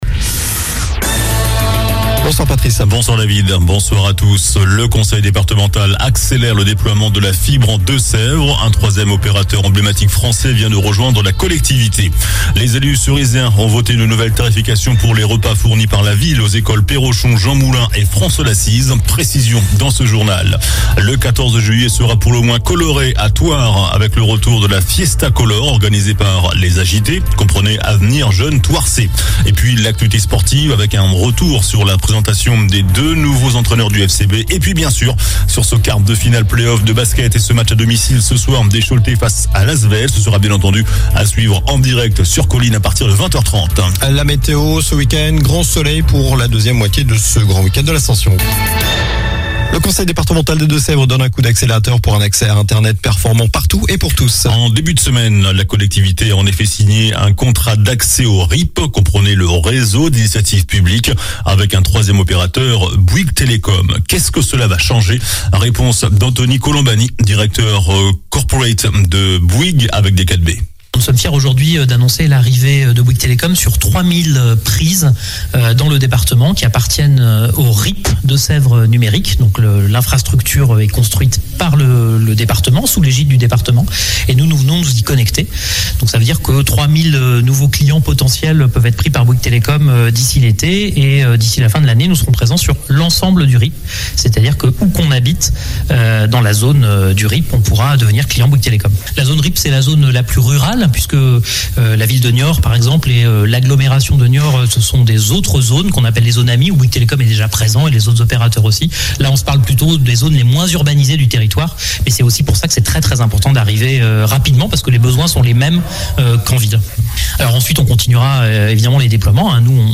JOURNAL DU VENDREDI 27 MAI ( SOIR )